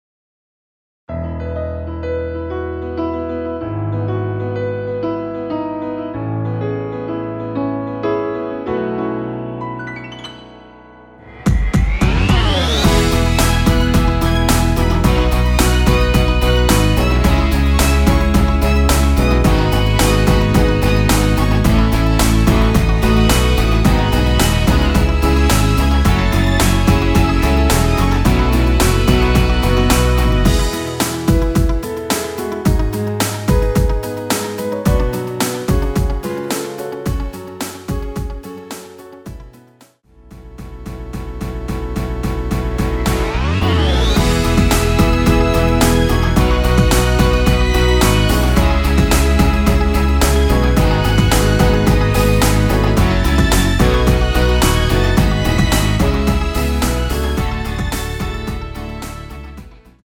원키에서(+4)올린 MR입니다.
앞부분30초, 뒷부분30초씩 편집해서 올려 드리고 있습니다.
중간에 음이 끈어지고 다시 나오는 이유는